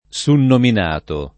sunnominato [ S unnomin # to ] agg.